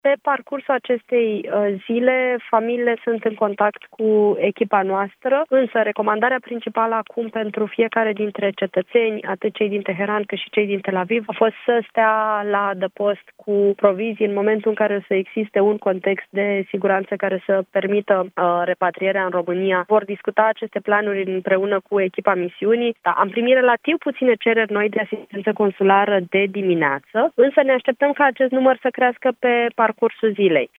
Cât despre românii din Iran, Oana Țoiu ne-a mai spus că recomandă sutelor de concetățeni să rămână la adăpost și să ceară ajutor dacă este cazul la Ambasadă sau Consulate: